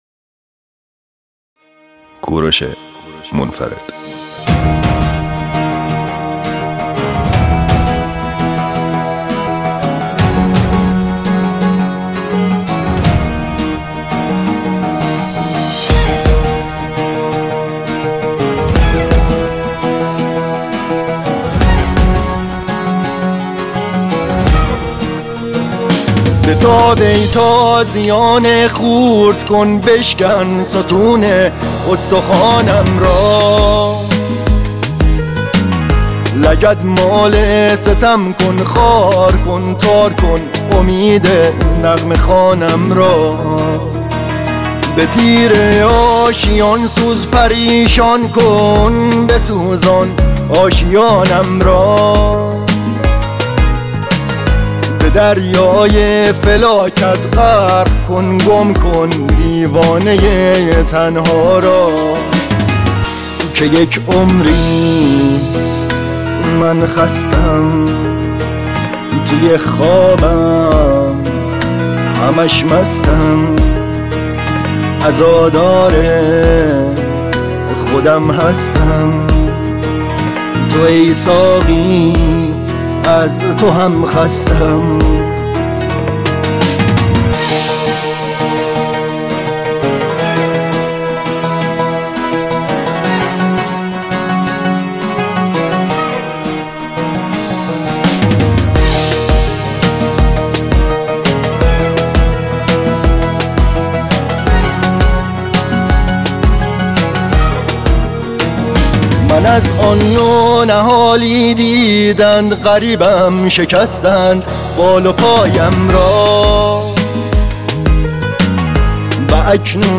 دسته بندی : دانلود آهنگ غمگین تاریخ : سه‌شنبه 20 نوامبر 2018